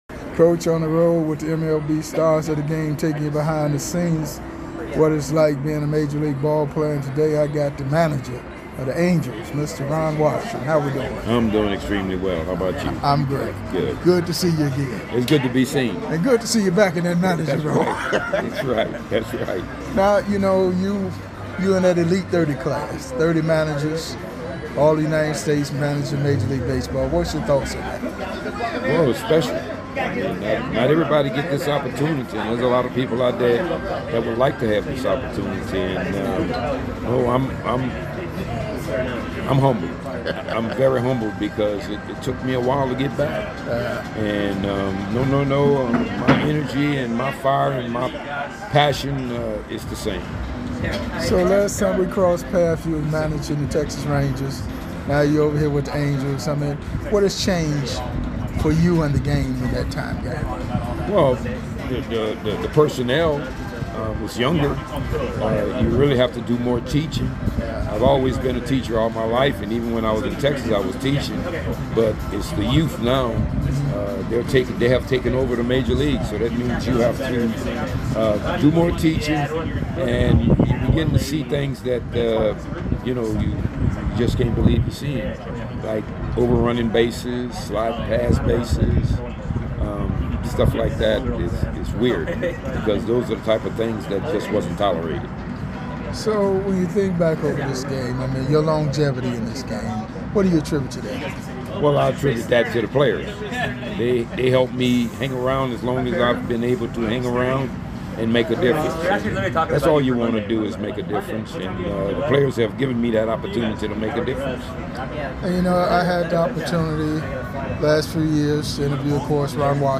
in-depth interviews with past and present MLB Stars of the game